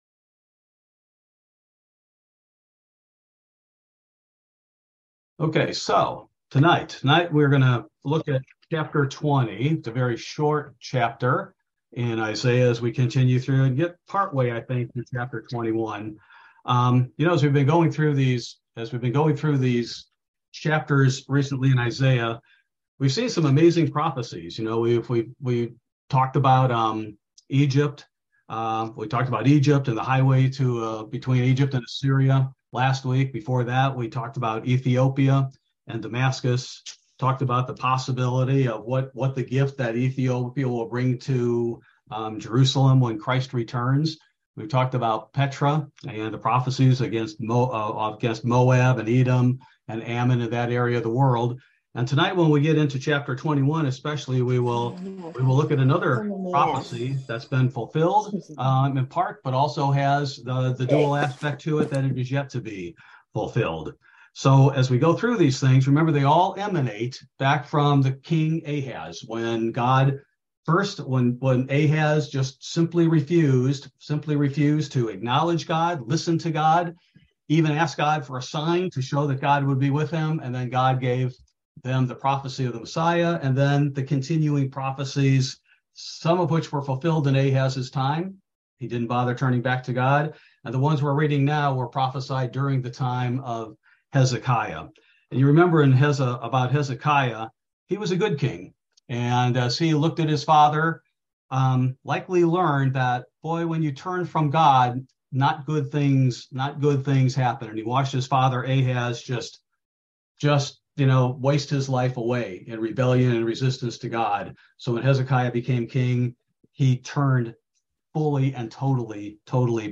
Bible Study: December 21, 2022